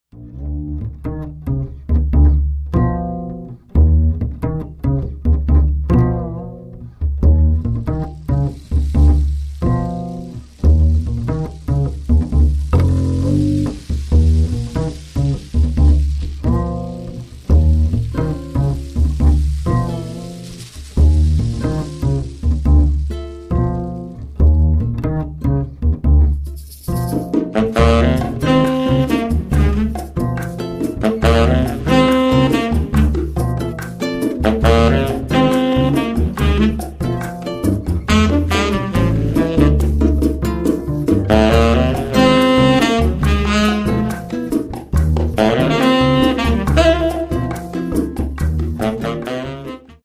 saxes, flutes, clarinets
acoustic guitar
double bass
percussion